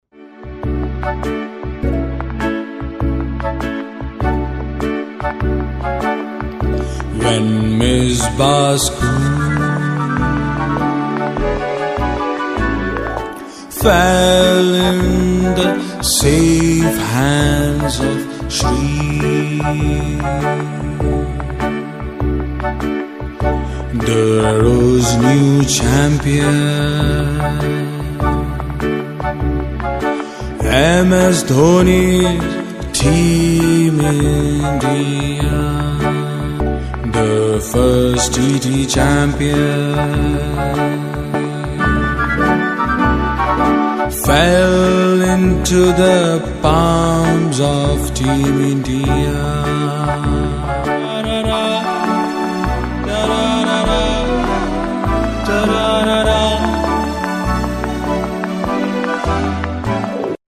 अपनी मधुर आवाज में प्रस्तुत किया है।
क्रिकेट प्रेमियों के लिए यह गीत एक भावनात्मक प्रस्तुति के साथ-साथ आगामी टी20 विश्व कप से पहले उत्साह को और भी बढ़ाने वाला है।